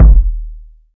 Kicks
KICK.85.NEPT.wav